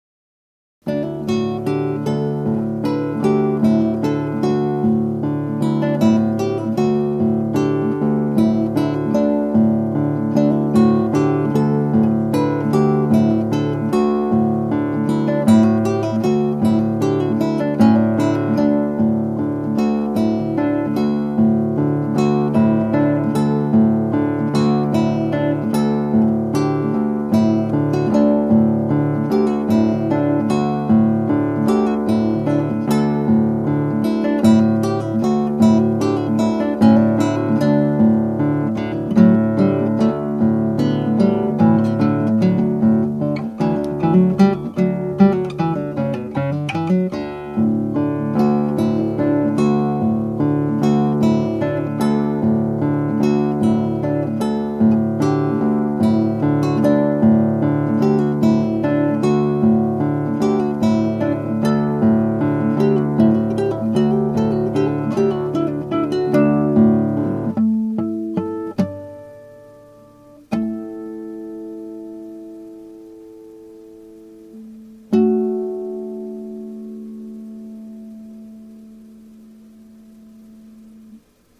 (アマチュアのクラシックギター演奏です [Guitar amatuer play] )
この曲はD、A、Dの太鼓のように高揚感を高める低音の伴奏がつづく上にメロディーを載せた形態をしています。ちょっと伴奏が耳につきます。
スラーによるメロディーは短目になってしまっています。
今回の演奏ではこの小節のハーモニックスの和音をフェルマータで伸ばしてから最後の和音を弾きました。
楽譜には速度指定はありませんがもう少し速く弾かれることが多いようです。